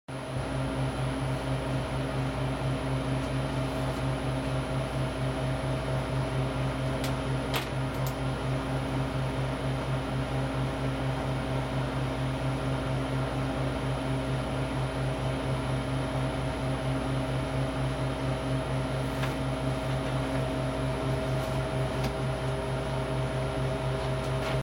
True T-43-HC Refrigerator Sound Analysis
This is the spectral analysis of the refrigerator in my allergist’s office that stores the allergen doses, as measured with my Smartphone. Spectral peaks occur at 60, 129, 133.5, 267, 401 & 641 Hz.
The 133.5 Hz peak has integer harmonics at 267 & 401 Hz. These peaks could represent tube internal acoustical frequencies.